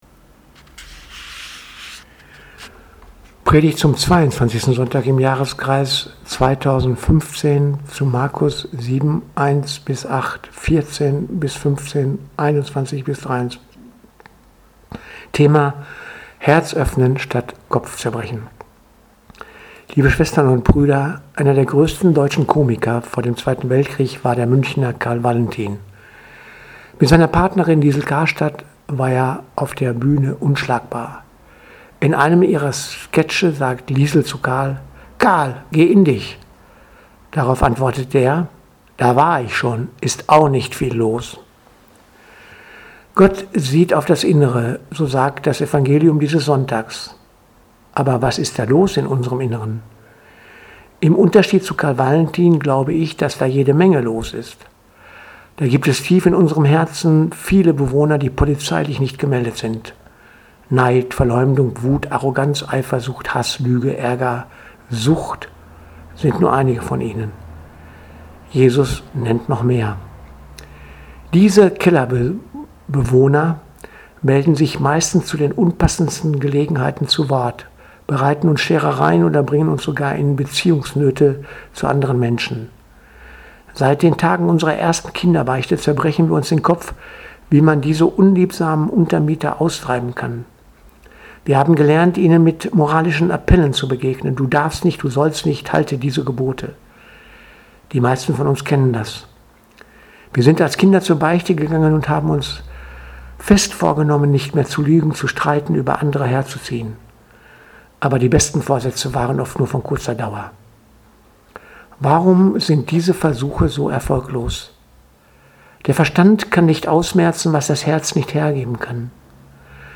Predigt zum 22. Sonntags im Jahreskreises 2015